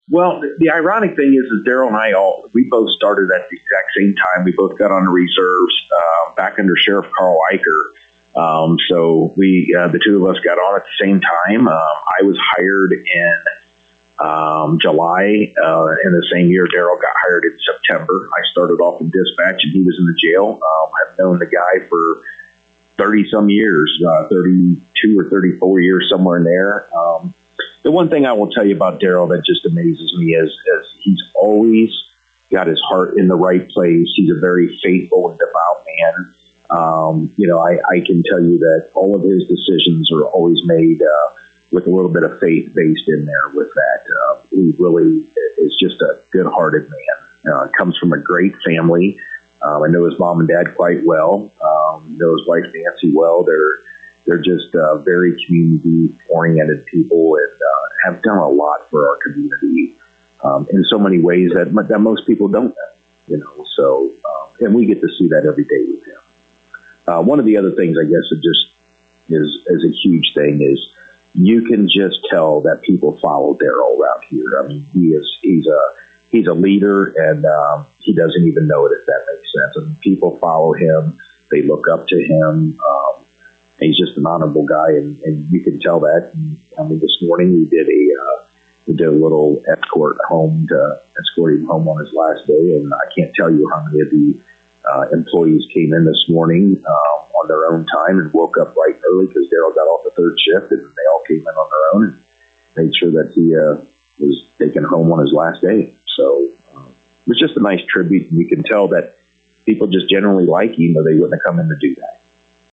To hear comments with Sheriff Doug Timmerman: